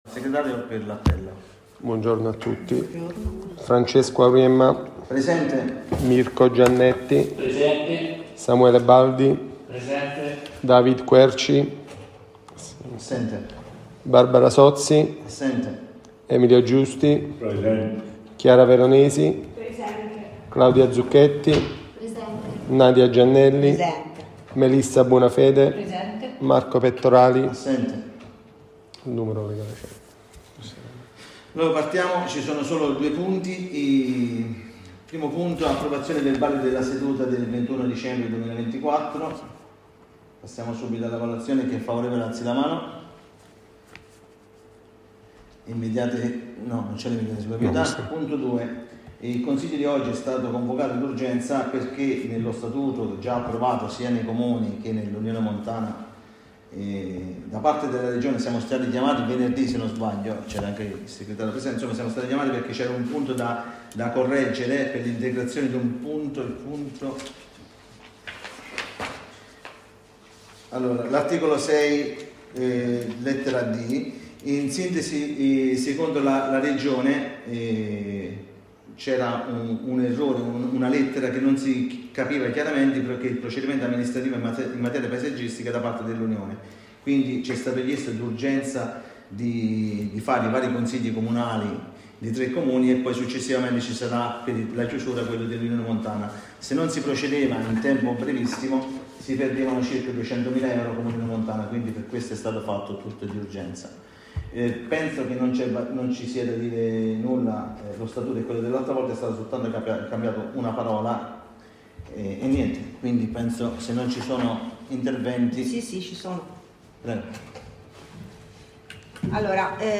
Consiglio Comunale del 18 Gennaio 2025 Comune di Montecatini Val di Cecina